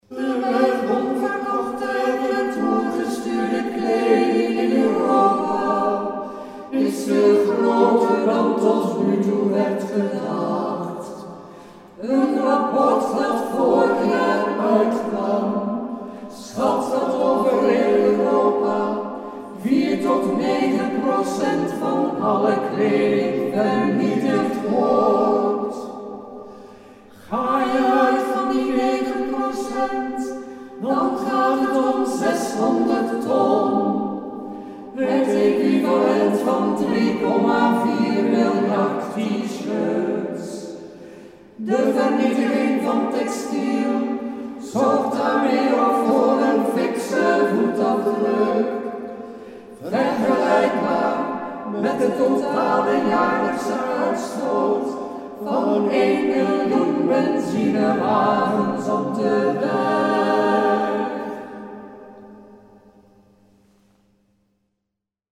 In kwartetjes gezongen op de wijze van vierstemmige Engelse ‘chants’: